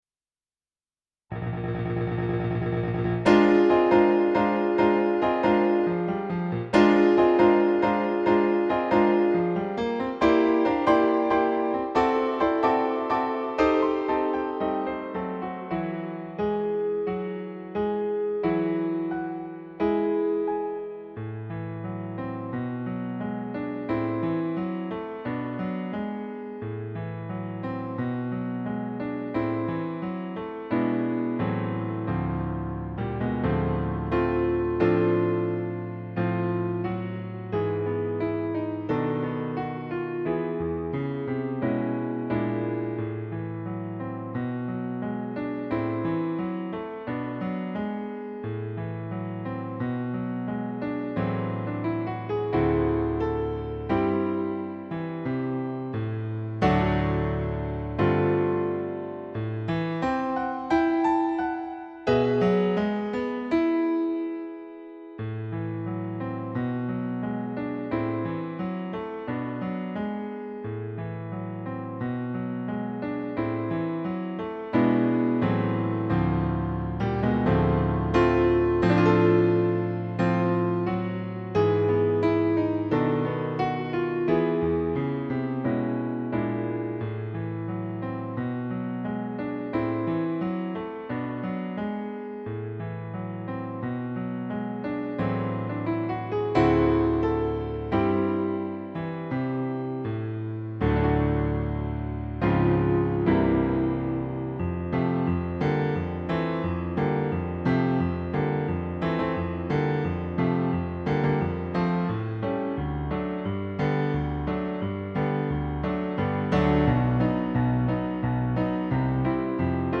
Porgy-And-Bess-Backing.mp3